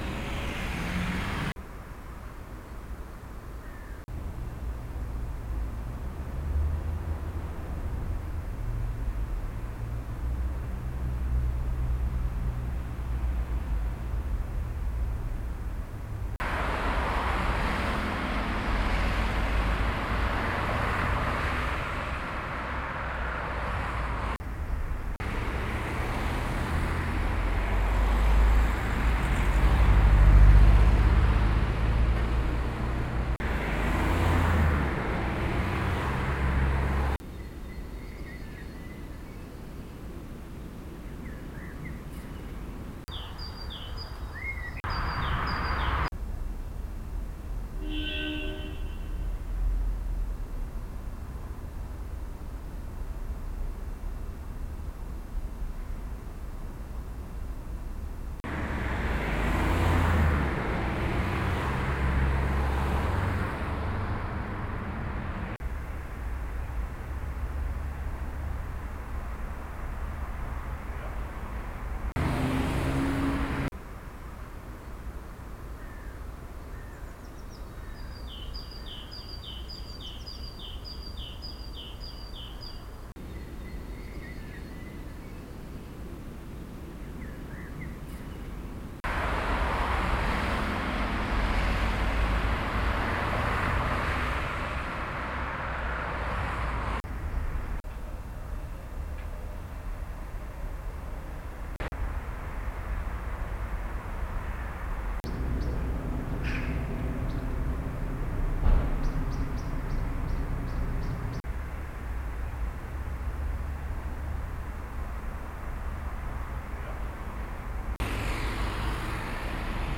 eindringlinge ist ein Fieldrecording Film aus Berlin Friedrichshain-Kreuzberg.
2. Version: Bild-Ton Verhältnis wird aufgelöst (Bild wird vom Ton getrennt und unabhängig voneinander komponiert)
aufgenommen mit Wavelab Lite durch Abspielen der Filme